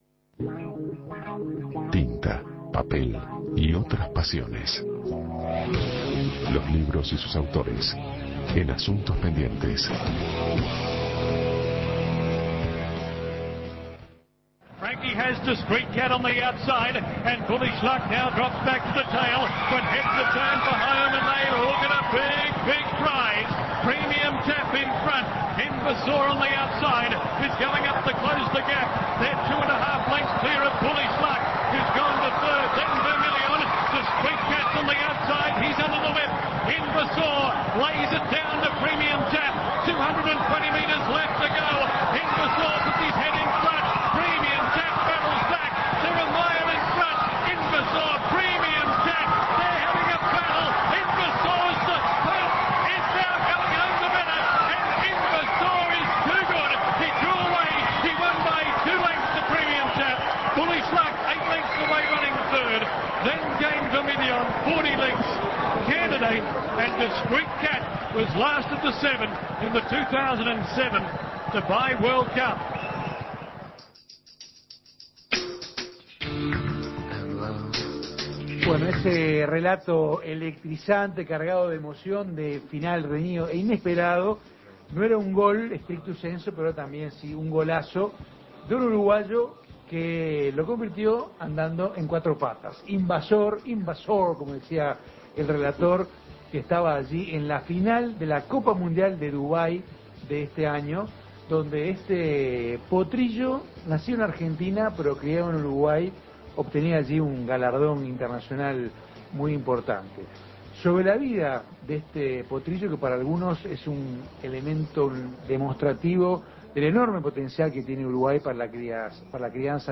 La vida de este caballo rioplatense multicampeón reconocido internacionalmente y que batió récords en premios y en su precio, ahora en un libro. Dos autores uruguayos recopilaron los datos de la biografía del mejor del mundo, un crack uruguayo en todas las pistas. Desde la Expo Prado 2007 un lugar lleno de campeones.